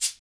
Index of /90_sSampleCDs/Northstar - Drumscapes Roland/PRC_Bonus Perc/PRC_Shakers x